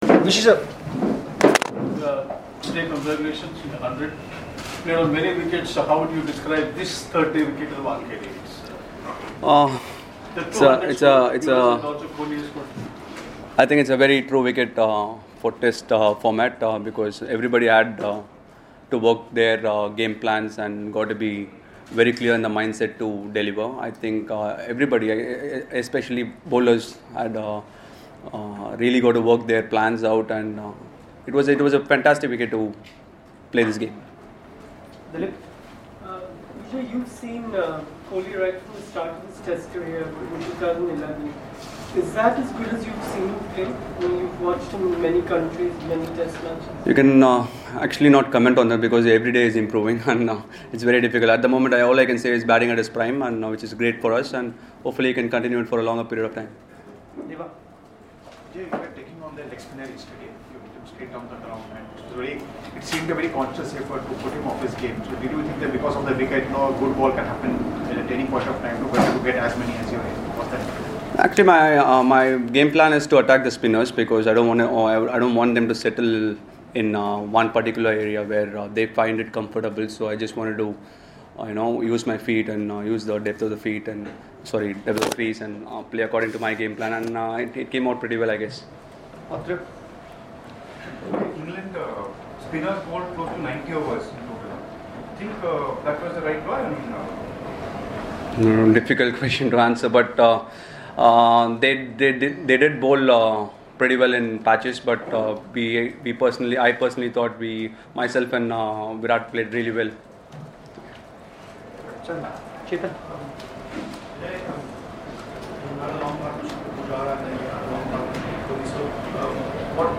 LISTEN: Murali Vijay speaks on his 8th Test Century